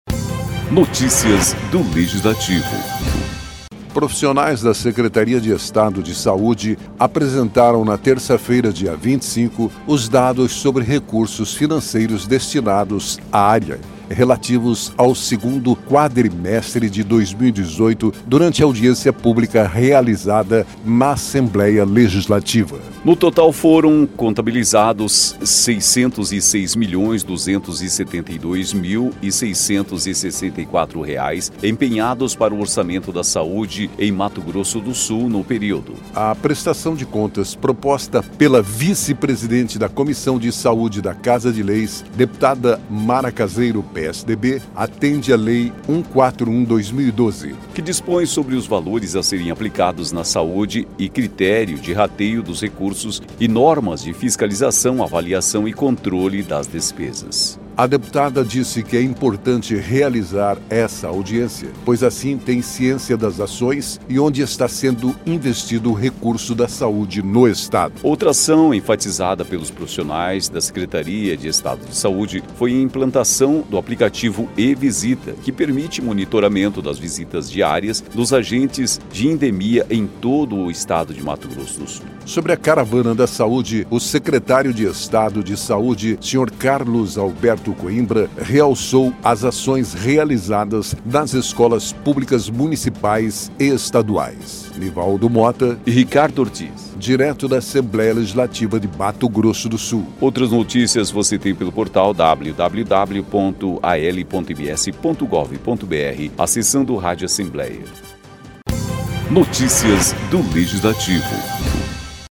Profissionais da Secretaria de Estado de Saúde (SES) apresentaram nesta terça-feira (25) os dados sobre recursos financeiros destinados à área, relativos ao segundo quadrimestre de 2018, durante audiência pública realizada na Assembleia Legislativa.